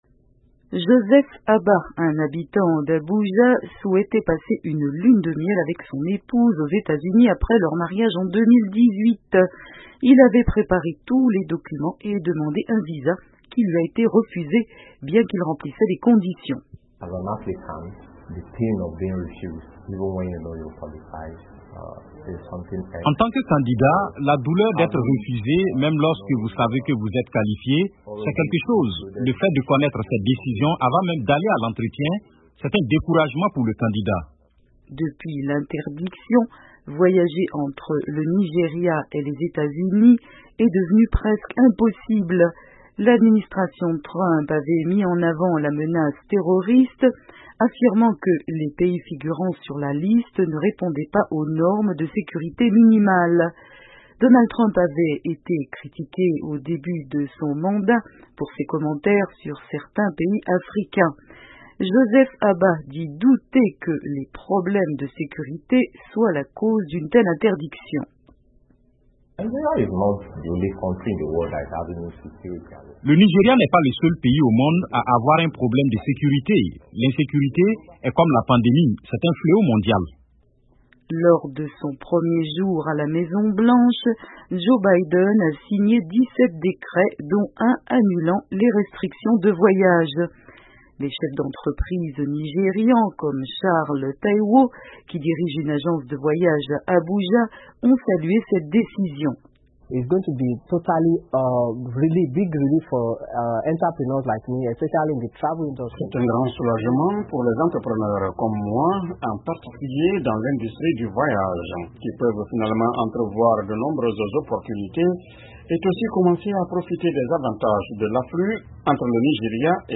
Le président américain Joe Biden a annulé les restrictions de voyage imposées par son prédécesseur à certains pays musulmans et africains. Le Nigéria faisait partie des pays touchés par ces restrictions imposées en 2017 par l'administration Trump pour raison sécuritaire. Un reportage